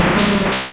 Cri de Grolem dans Pokémon Rouge et Bleu.